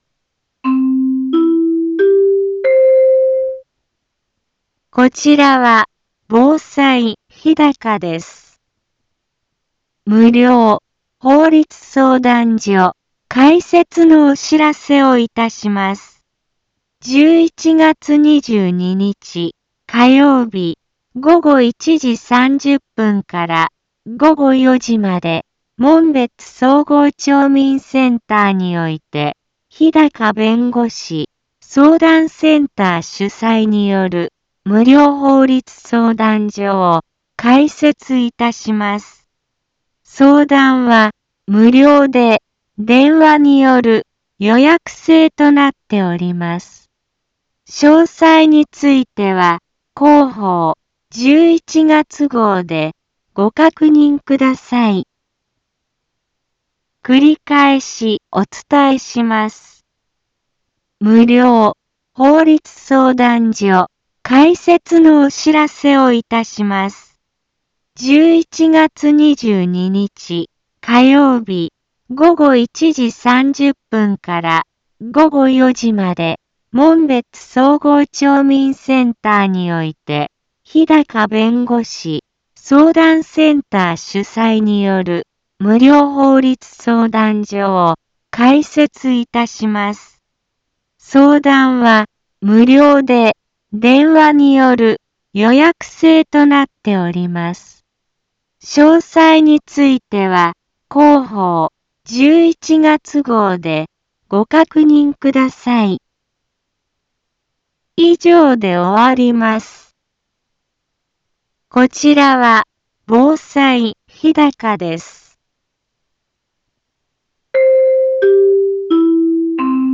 一般放送情報
Back Home 一般放送情報 音声放送 再生 一般放送情報 登録日時：2022-11-15 10:04:12 タイトル：無料法律相談会のお知らせ インフォメーション：こちらは防災日高です。 無料法律相談所開設のお知らせをいたします。